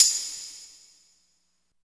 27 V.TAMB -R.wav